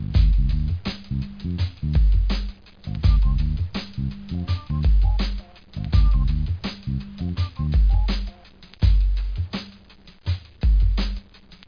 Sound Factory: RAP